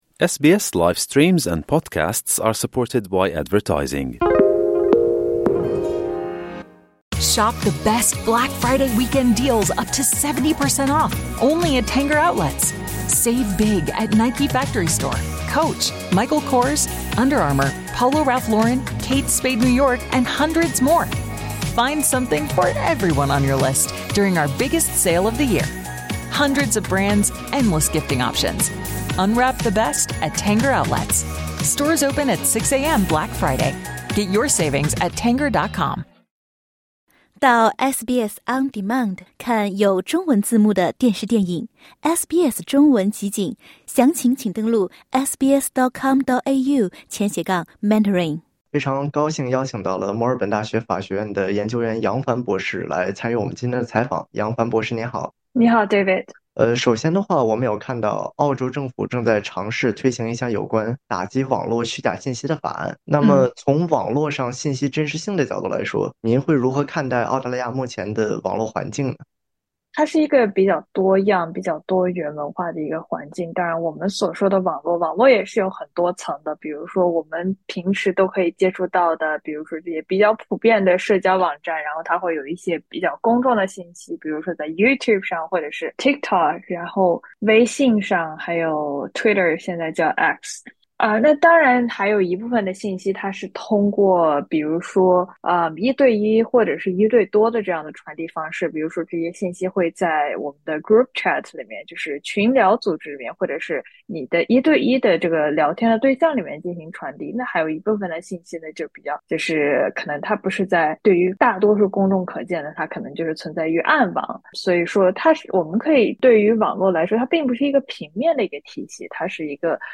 （点击音频，收听详细报道）